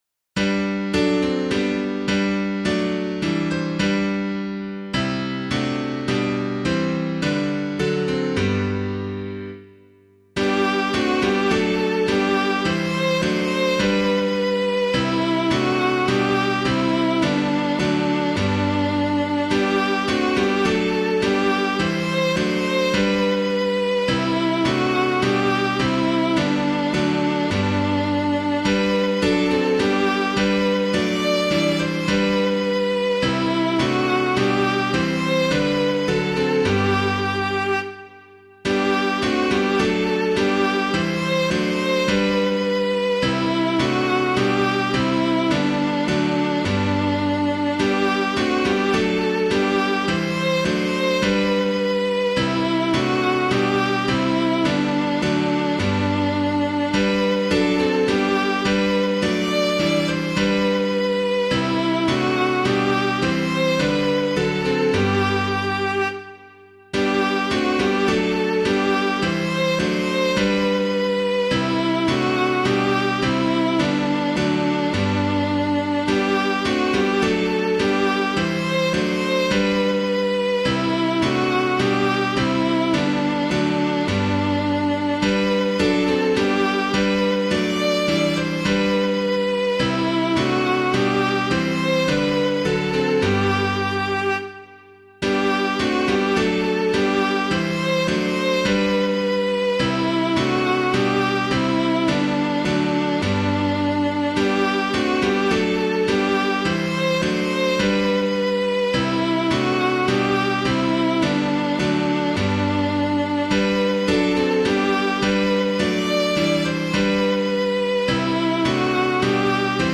piano
For the Beauty of the Earth [Pierpoint - DIX] - piano.mp3